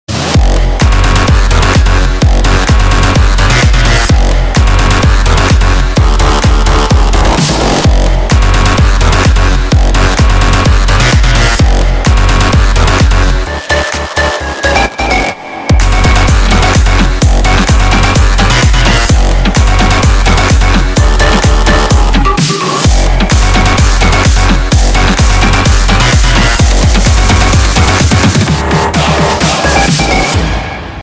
DJ铃声